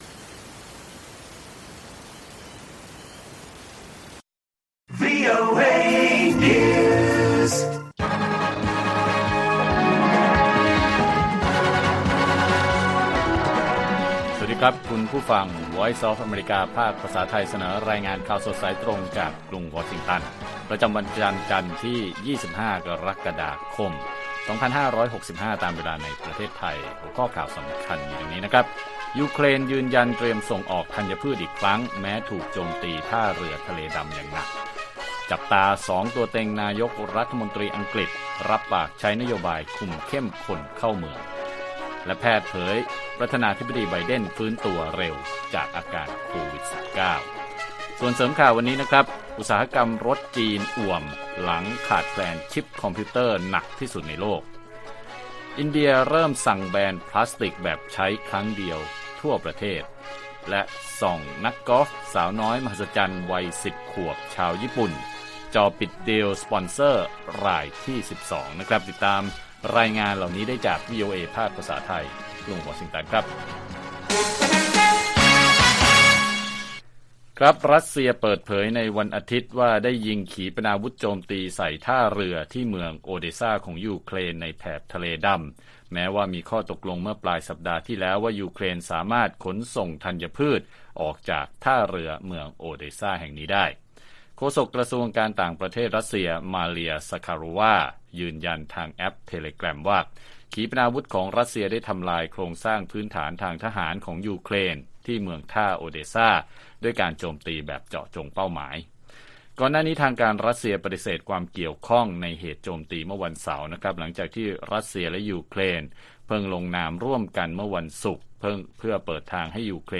ข่าวสดสายตรงจากวีโอเอไทย 25 ก.ค. 2565